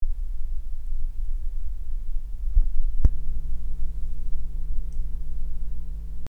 Das AKG 170 hat eine Art Netzbrummen.
Hier mal ein Soundbeispiel, erst fasse ich an, danach lasse ich los und es kommt das Brummen. (Gain ist jetzt jenseits von gut und böse aufgerissen damit man es deutlich hört). Das Rauschen ist bei dem Mic subjektiv auch lauter als bei den anderen Mics (habe ich aber noch nicht ordentlich verglichen).